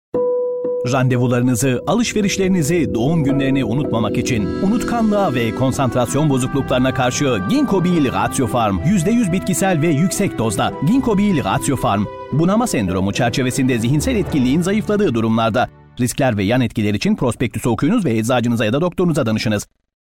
Sprecher türkisch.
Sprechprobe: Werbung (Muttersprache):